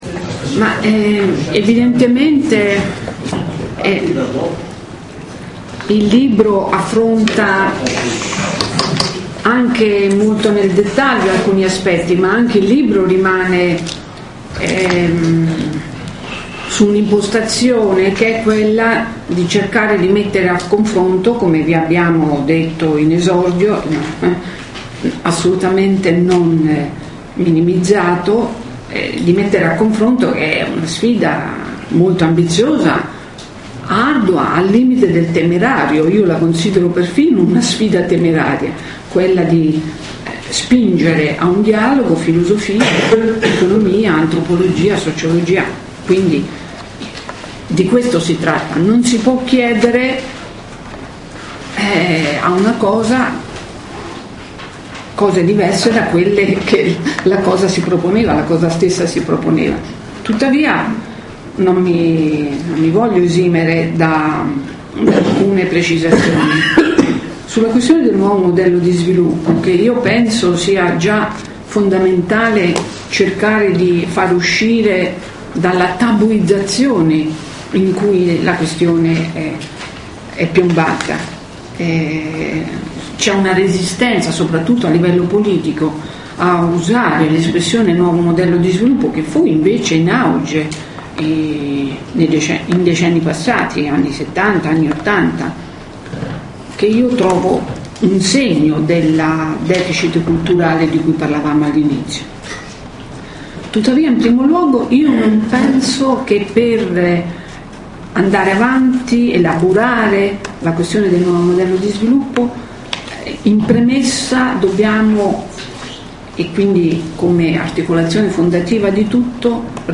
premessa di Giovanni Bianchi